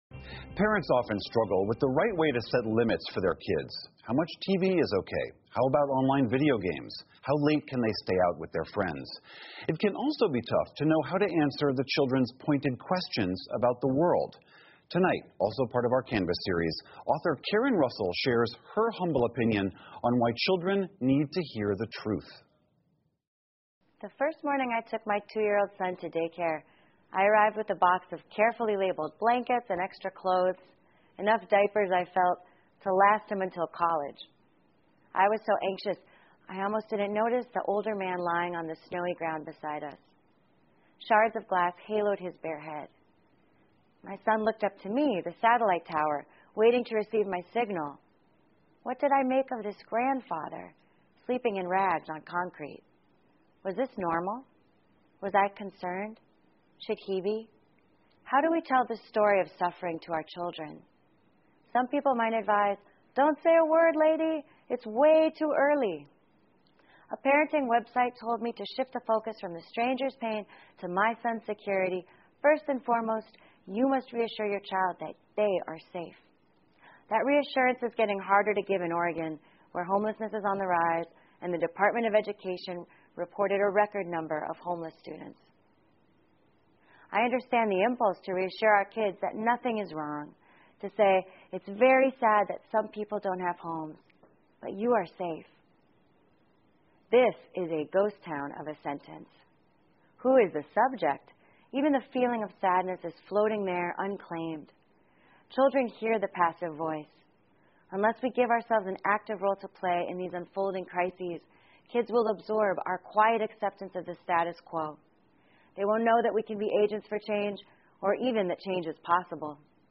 美国公共电视网PBS高端访谈节目，其所提供的高质量节目与教育服务，达到媒体告知(inform)、启发(inspire)与愉悦(delight)的社会责任。